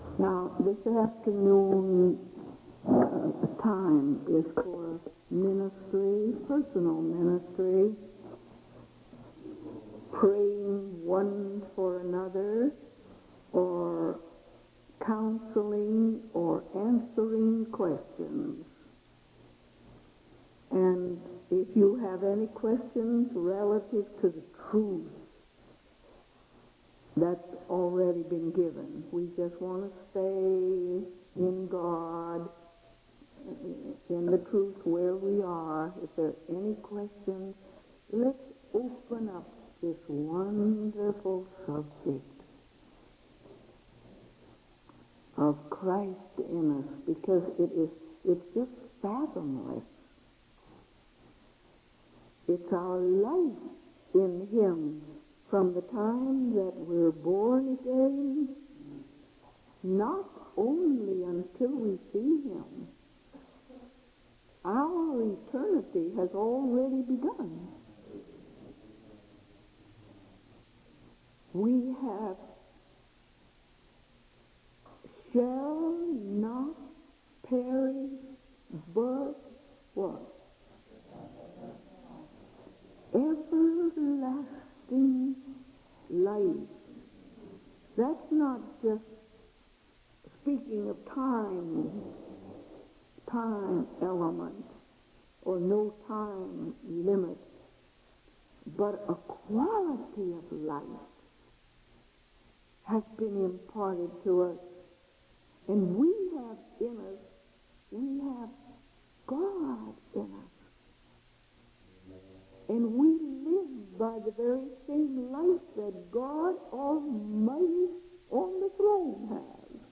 In this sermon, the speaker emphasizes the process of spiritual growth and transformation. He uses the analogy of a seed and the four seasons to illustrate how God's work in our lives takes time. The speaker also highlights the importance of yielding to the Holy Spirit and refusing to give in to the temptations of the enemy.